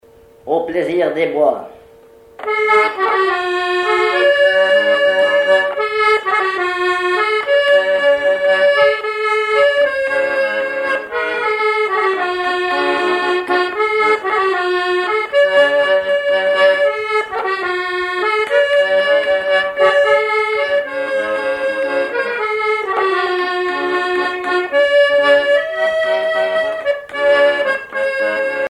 Mémoires et Patrimoines vivants - RaddO est une base de données d'archives iconographiques et sonores.
accordéon(s), accordéoniste
valse musette
Pièce musicale inédite